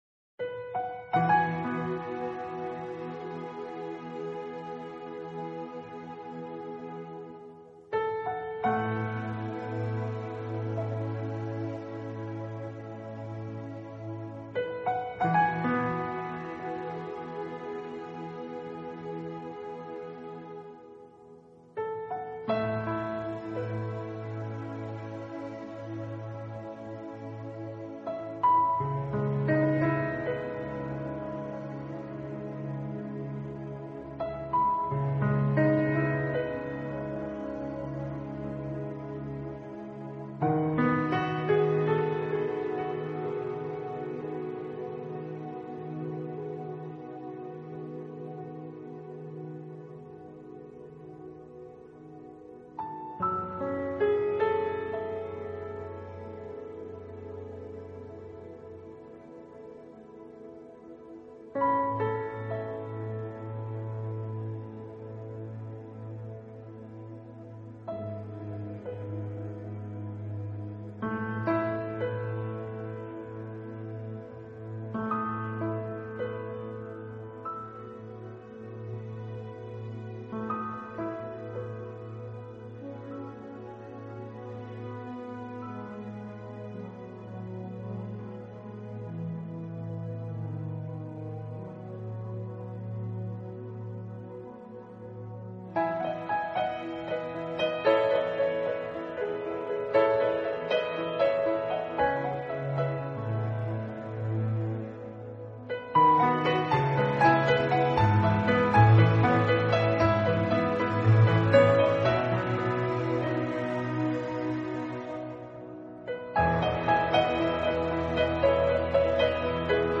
钢琴专辑
版本：水晶钢琴
山水，他水晶般清澈的钢琴音色，呈现了大自然的安详自在，洗涤了纷杂的思绪，